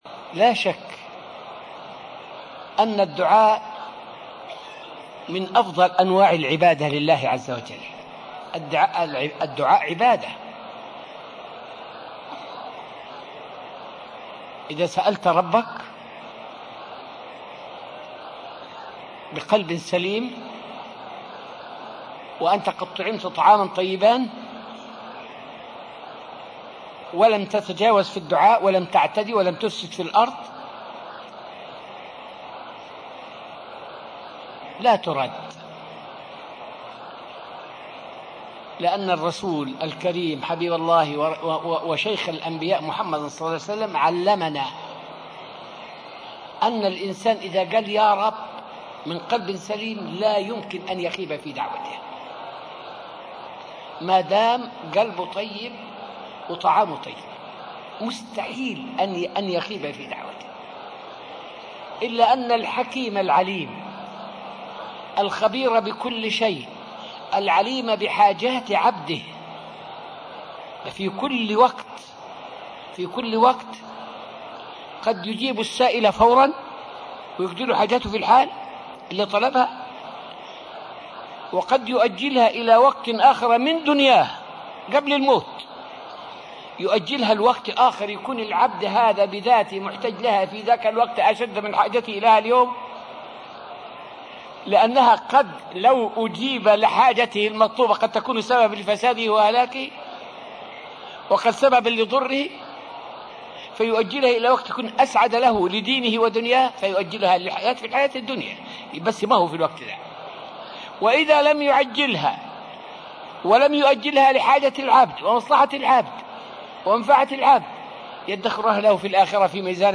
فائدة من الدرس الثاني والعشرون من دروس تفسير سورة البقرة والتي ألقيت في المسجد النبوي الشريف حول متى يستجيب الله للدعاء.